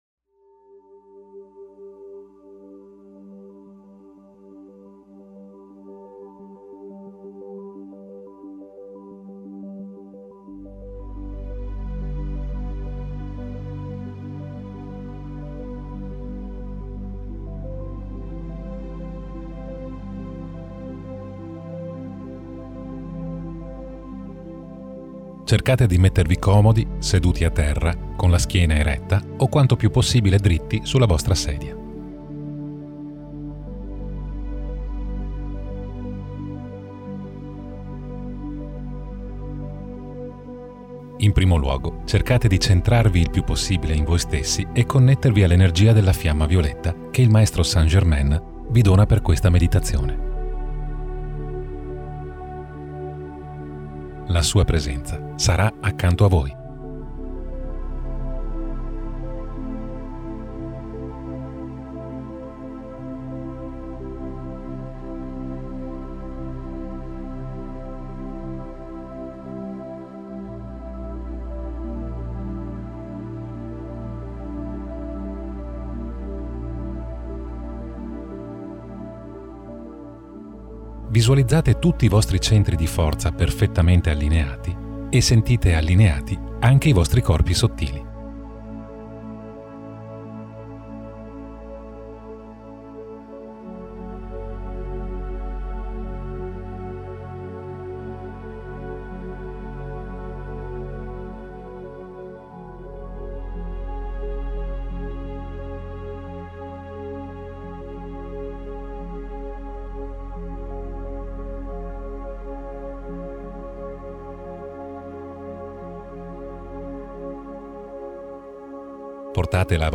Puoi ascoltare la meditazione senza interruzioni pubblicitarie inserite da YouTube direttamente da qui: MEDITAZIONE CON LA FIAMMA VIOLETTA Per salvare il file mp3 clicca col tasto destro del mouse sul link e scegli "salva con nome"